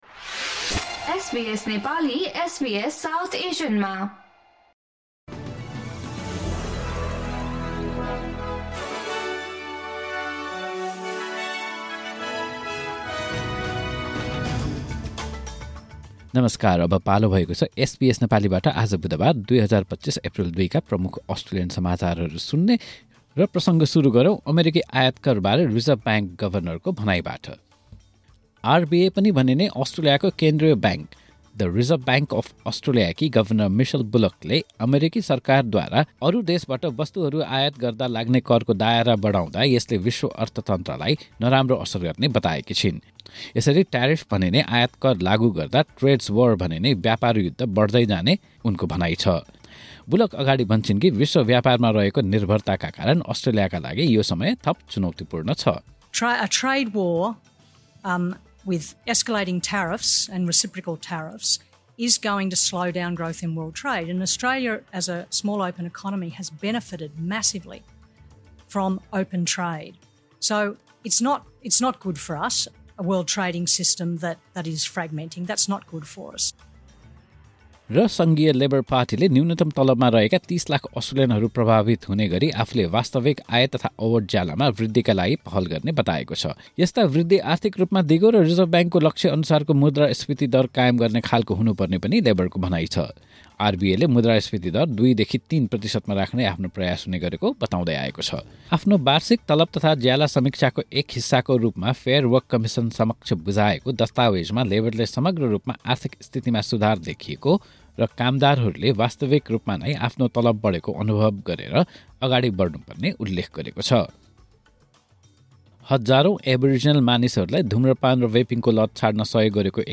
SBS Nepali Australian News Headlines: Wednesday, 2 April 2025